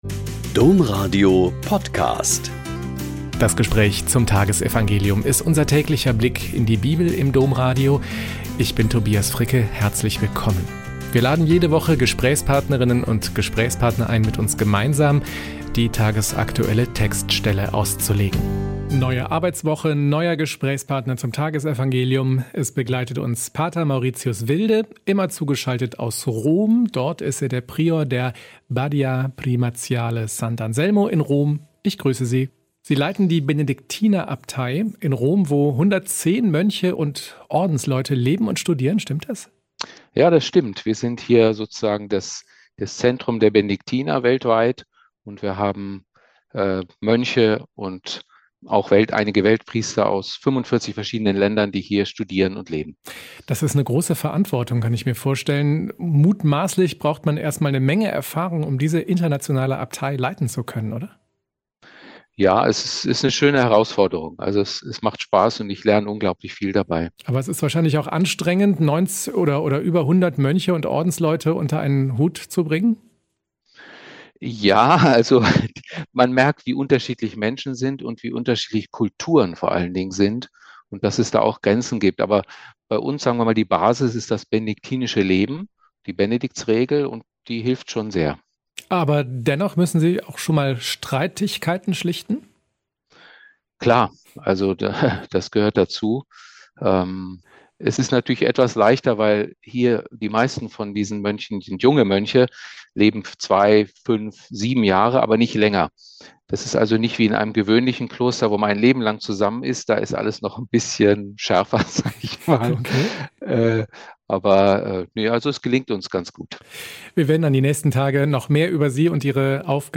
Lk 18,35-43 - Gespräch